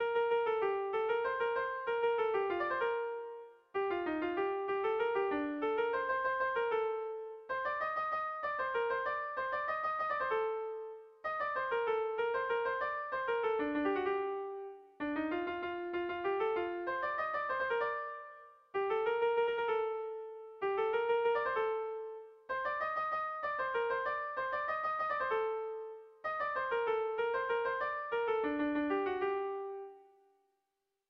Sentimenduzkoa
ABD..